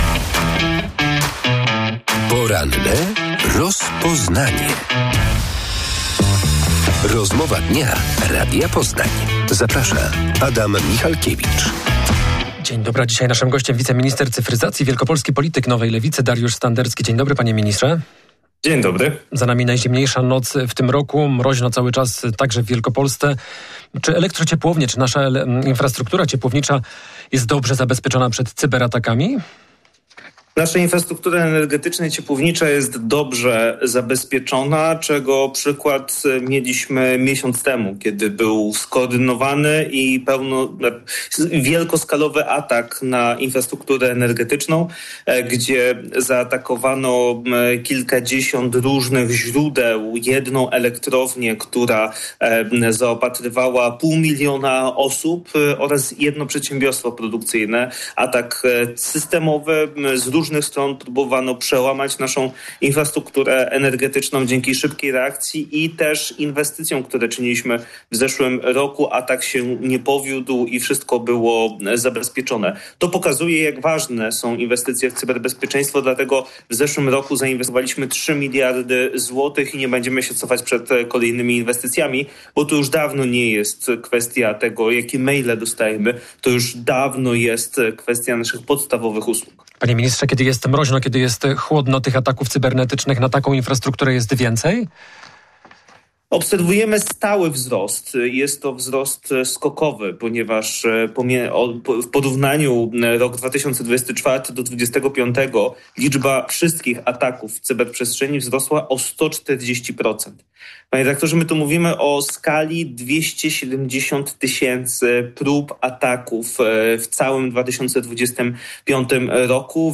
Hakerzy atakują polskie elektrownie i firmy wodociągowe. W porannej rozmowie Radia Poznań wiceminister cyfryzacji Dariusz Standerski przekazał dziś, że w ubiegłym roku liczba wszystkich cyberataków w naszym kraju wzrosła o 140 procent.